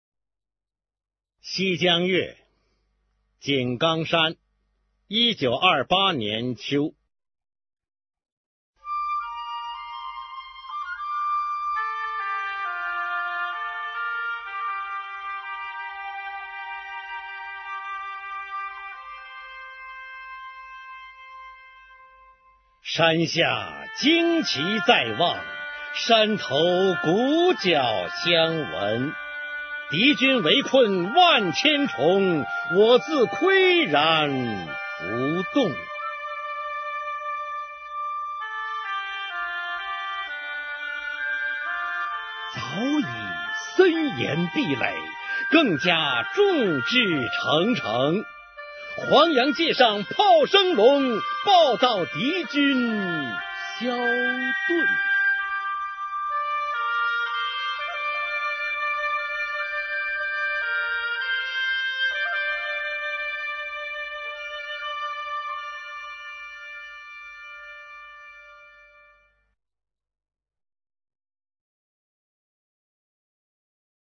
[毛泽东诗词朗诵]毛泽东-西江月·井冈山（男） 配乐朗诵